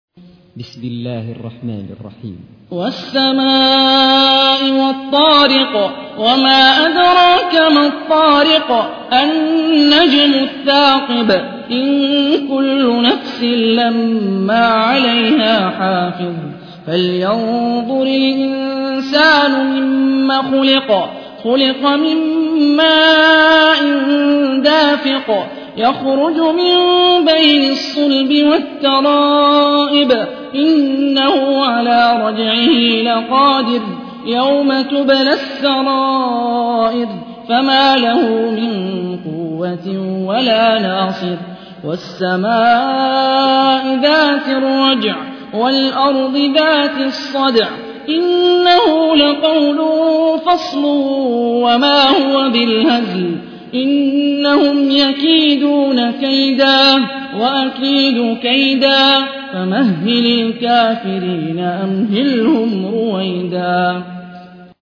تحميل : 86. سورة الطارق / القارئ هاني الرفاعي / القرآن الكريم / موقع يا حسين